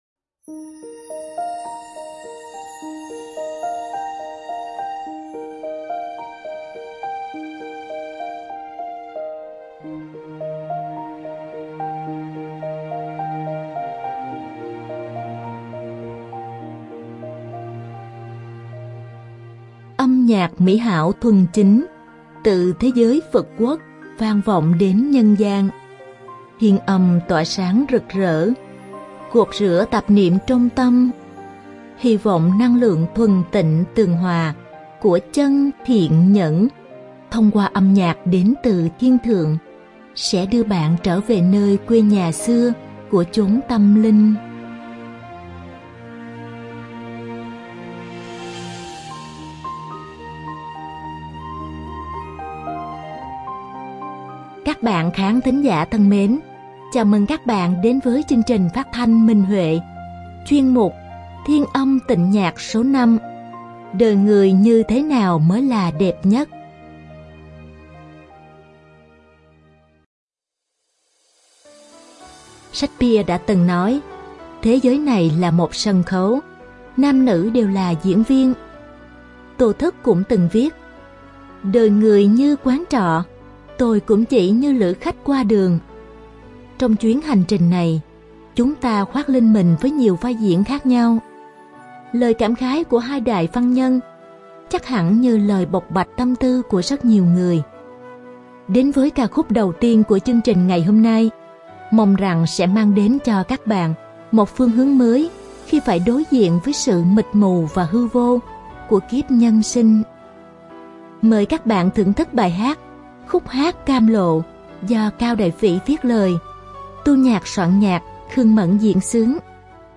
Ca khúc: Khúc hát cam lộ 2.
Độc tấu đàn tỳ bà